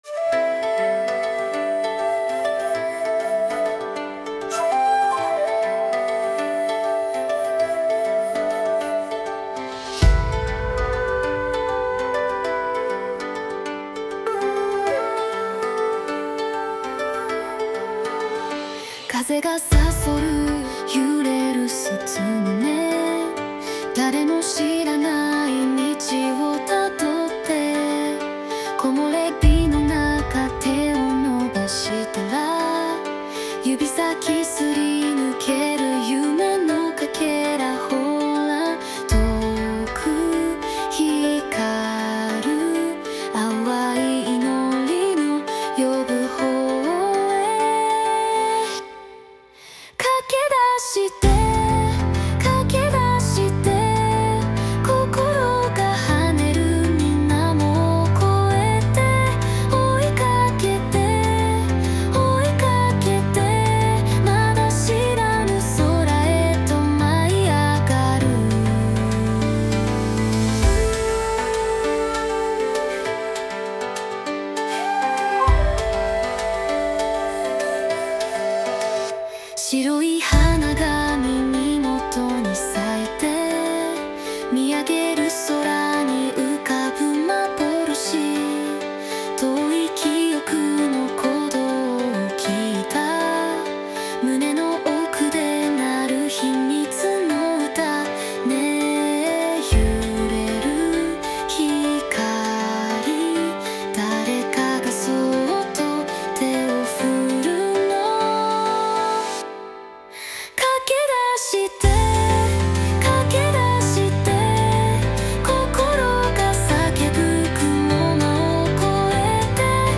女性ボーカル邦楽邦楽 女性ボーカルエンドロール披露宴BGM入場・再入場和風ノスタルジック幻想的
著作権フリーオリジナルBGMです。
女性ボーカル（邦楽・日本語）曲です。
日本の伝統音楽にのせて、どこか懐かしくて、それでいて新しい――